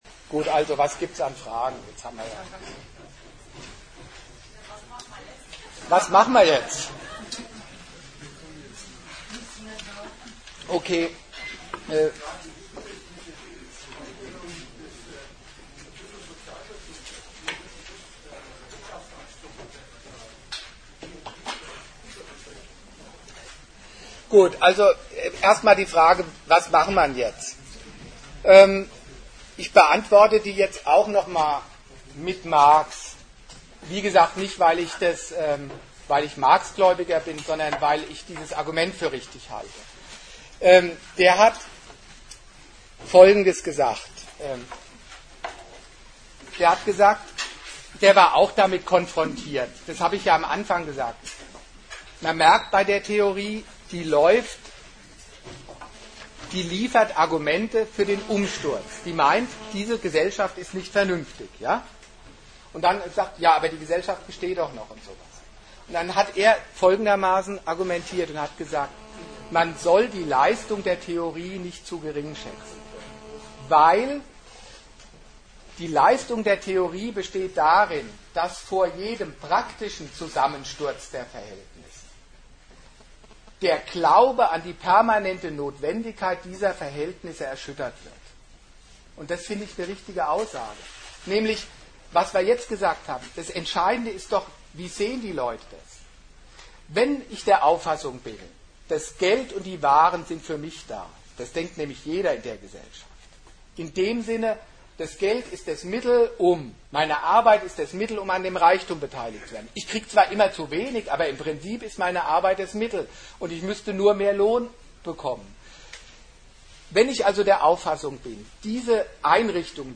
Datum 30.04.2008 Ort München Themenbereich Arbeit, Kapital und Sozialstaat Veranstalter AK Gegenargumente Dozent Gastreferenten der Zeitschrift GegenStandpunkt Linke Parteien zählen den Theoretiker des 19.